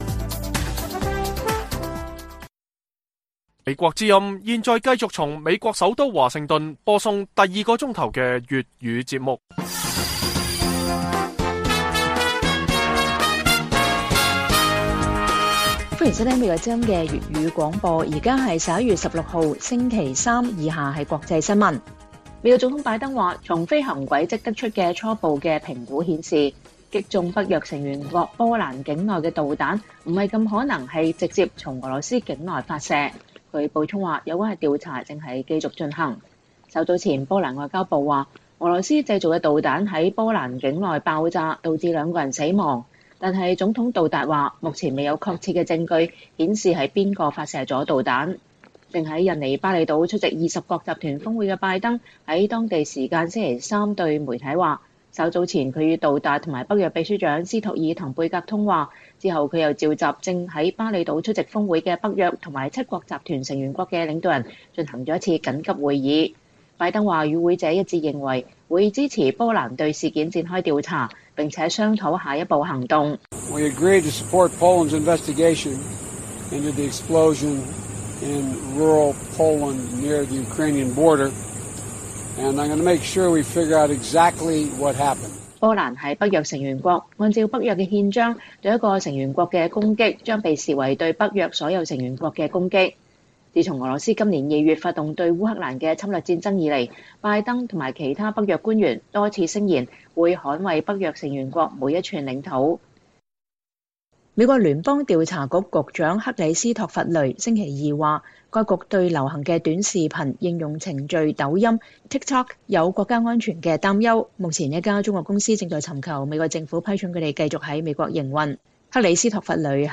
粵語新聞 晚上10-11點: 北約調查波蘭遭受導彈襲擊